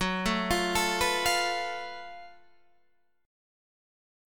F#mM11 chord